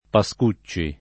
[ pa S k 2©© i ]